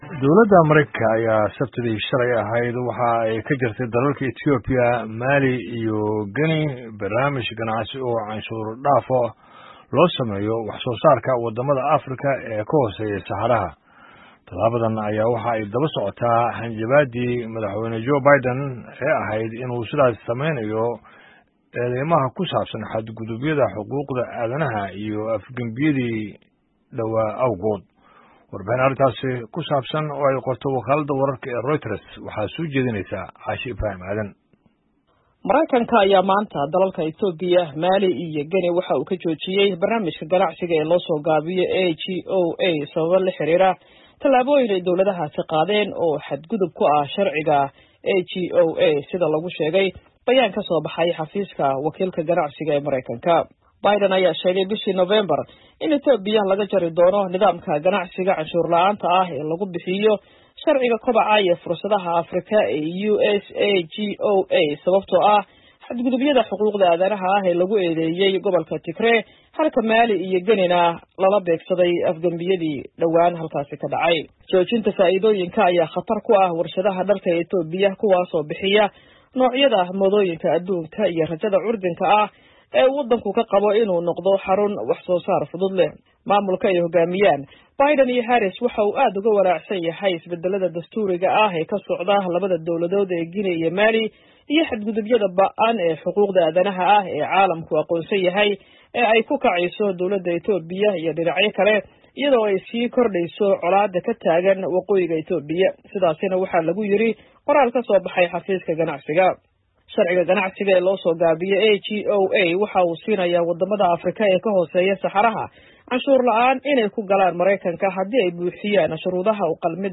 War Deg-Deg ah